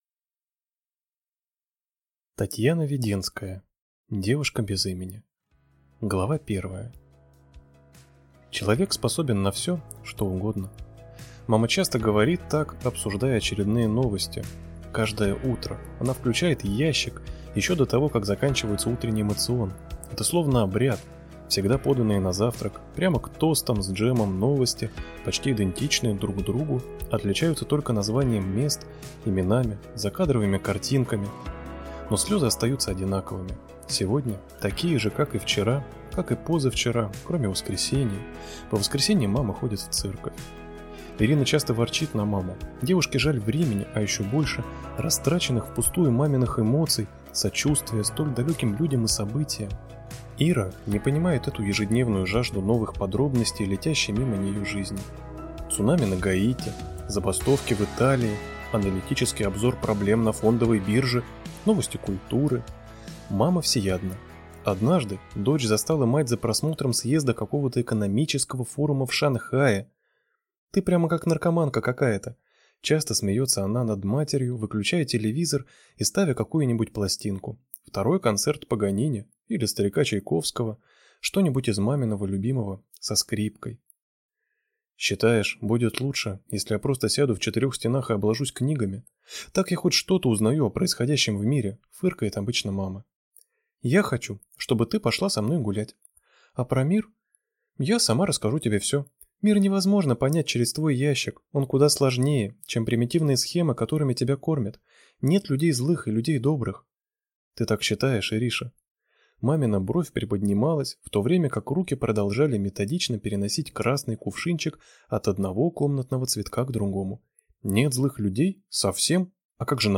Аудиокнига Девушка без имени | Библиотека аудиокниг